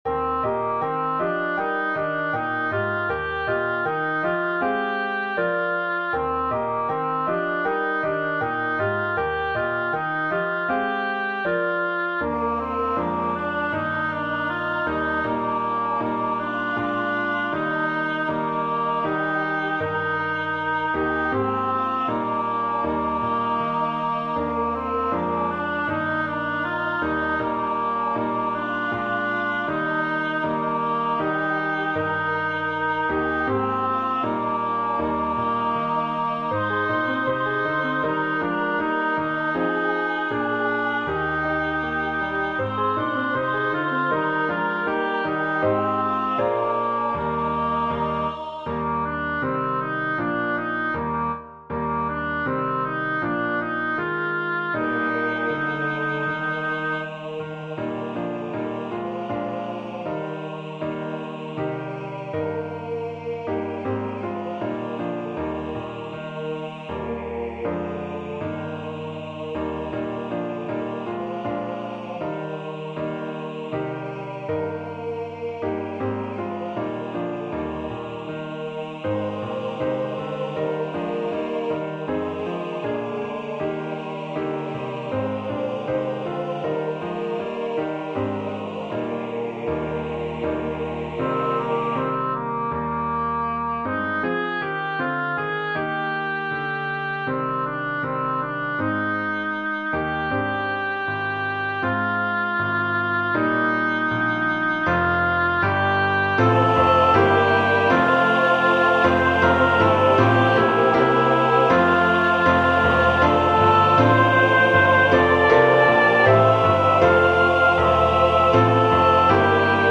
Once, In Royal David's City (by Ben Alder -- Oboe, SATB)
Voicing/Instrumentation: SATB , Oboe We also have other 29 arrangements of " Once, In Royal David's City ".